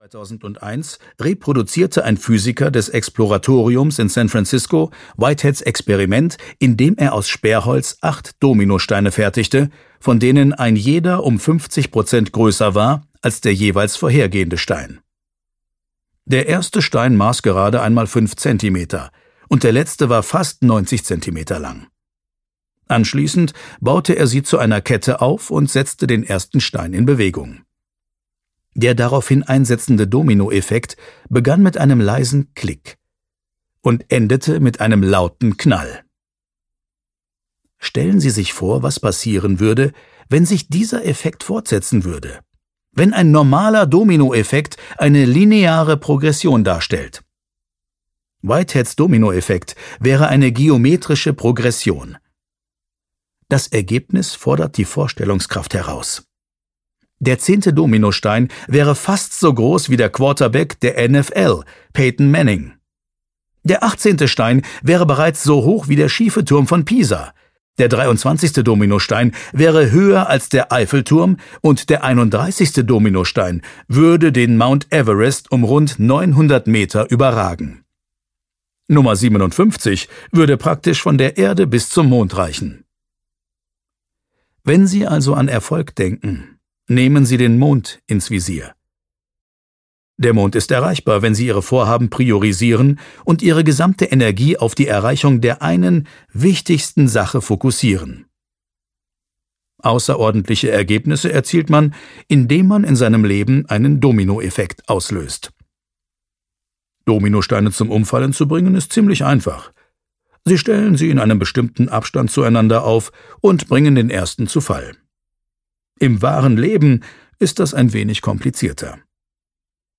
Hörbuch The One Thing, Gary Keller.